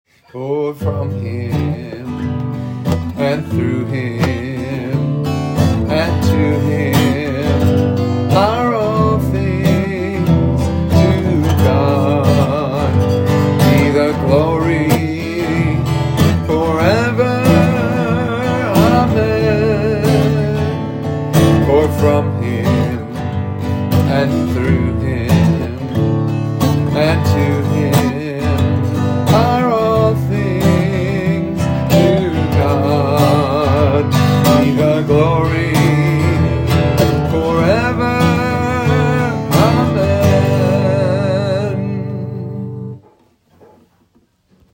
Transpose from G